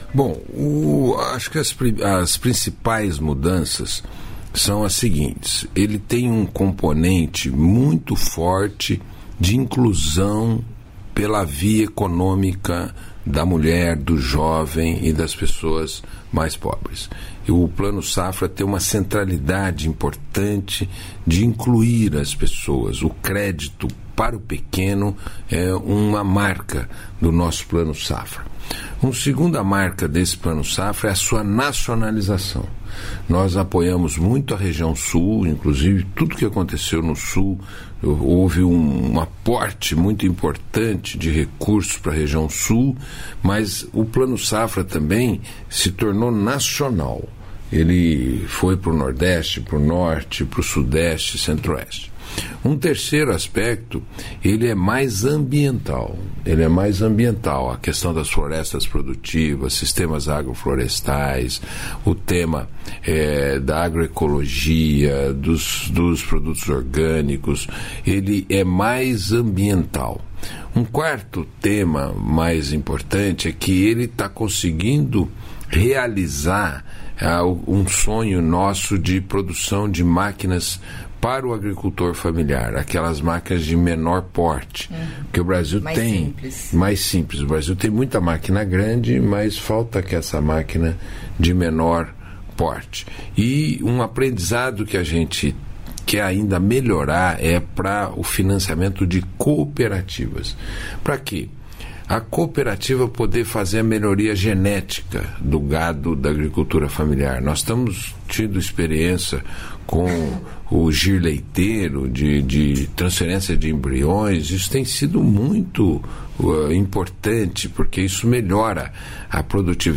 Trecho da participação do ministro do Desenvolvimento Agrário e Agricultura Familiar, Paulo Teixeira, no programa "Bom Dia, Ministro", desta terça-feira (1), nos estúdios da EBC, em Brasília (DF).